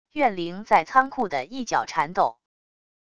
怨灵在仓库的一角缠斗wav音频